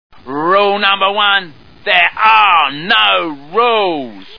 The Simpsons [Celebrities] Cartoon TV Show Sound Bites